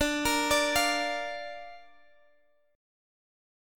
Listen to Dm#5 strummed